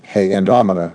synthetic-wakewords
ovos-tts-plugin-deepponies_Barack Obama_en.wav